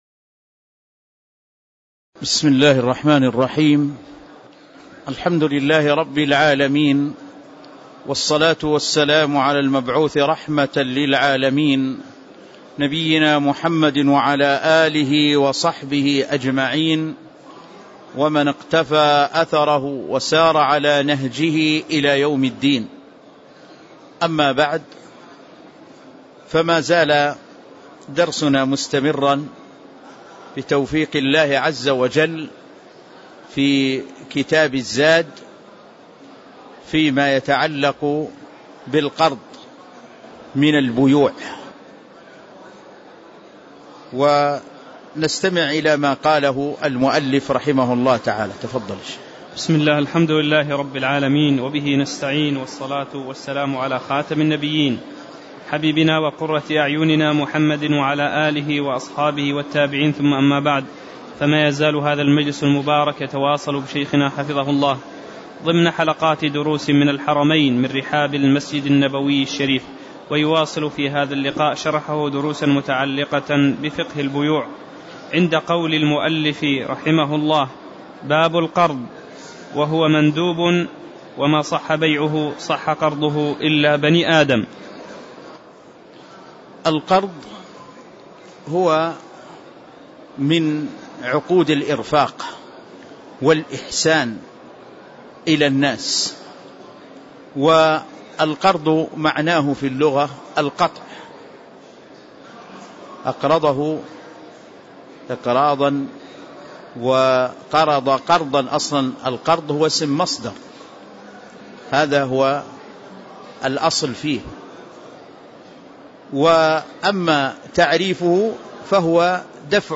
تاريخ النشر ٧ محرم ١٤٣٧ هـ المكان: المسجد النبوي الشيخ